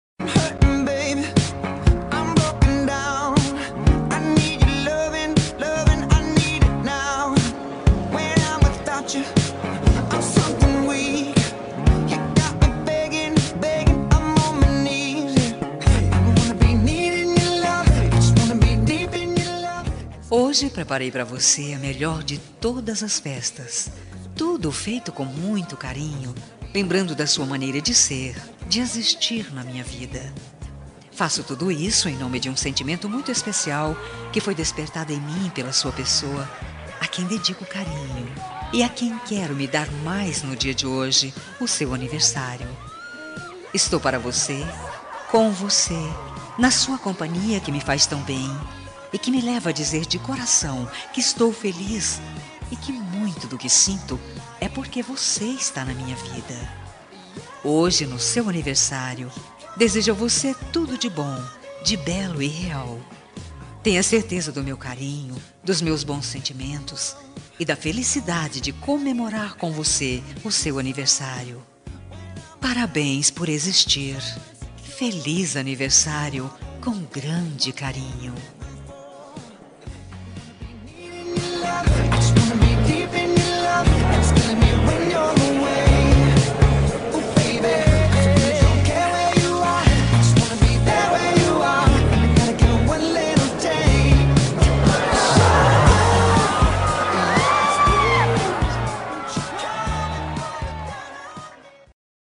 Aniversário de Ficante – Voz Feminina – Cód: 8874
aniv-de-ficante-fem-8874.m4a